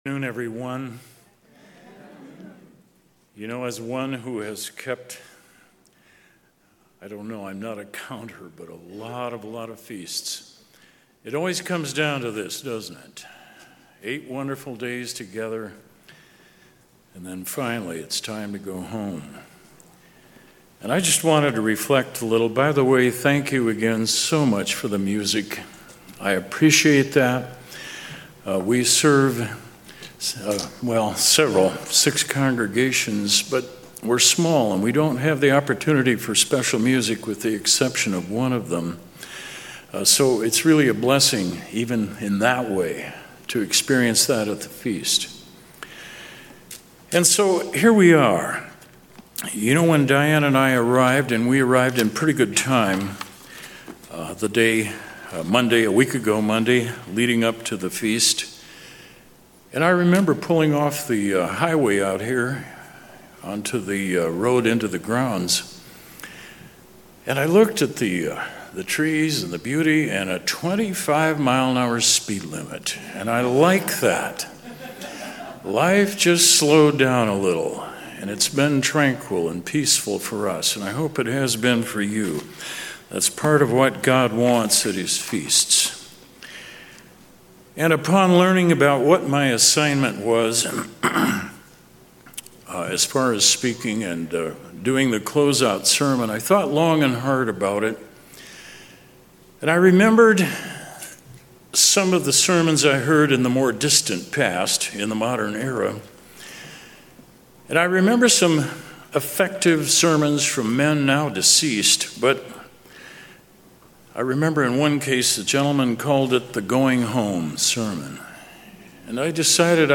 No matter what comes our way, we must be assured God will see us through. This sermon seeks to provide encouragement and guidance on holding fast as we continue our individual journey to the Kingdom of God.